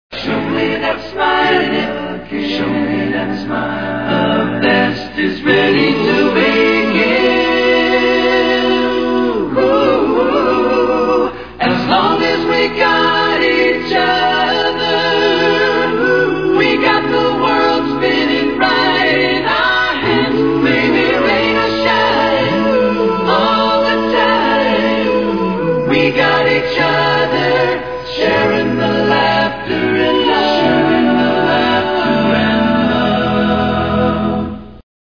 Générique a capella (1991)